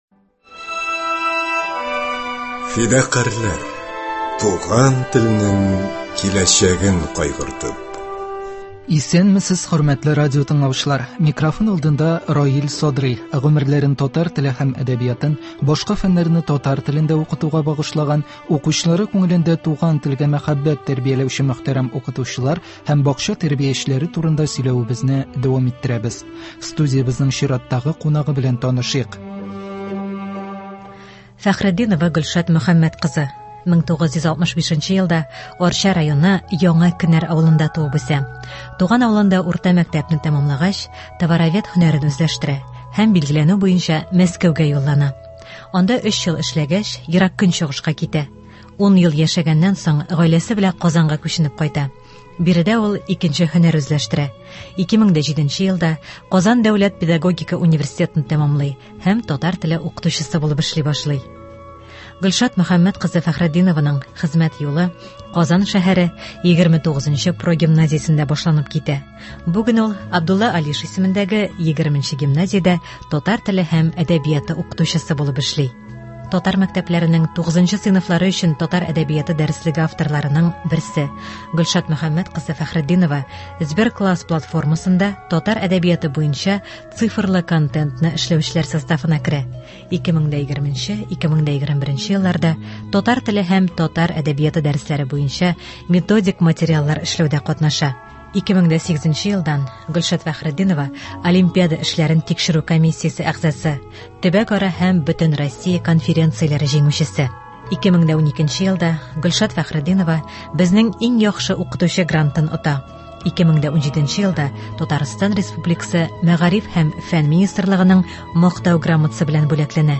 Студиябезнең чираттагы кунагы